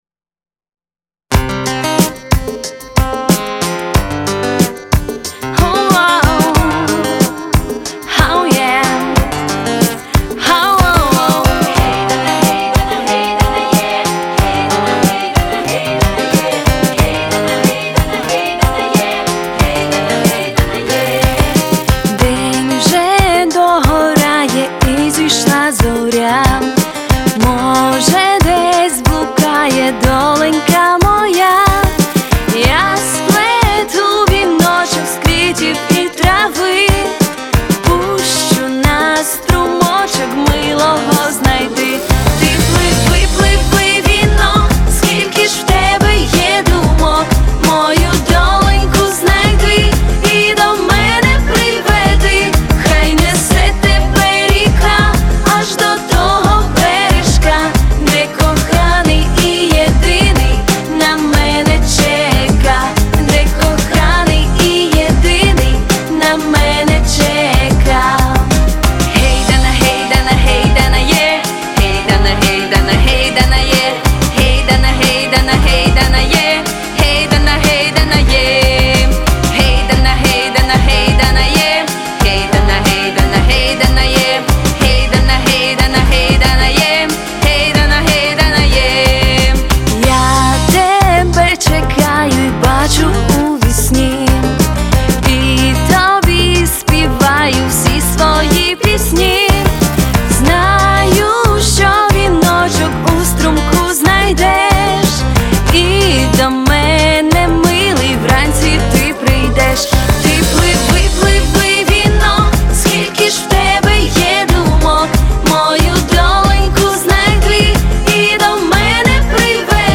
Слова: Віталія Войтка
Красива українська пісня.Чудове виконання. friends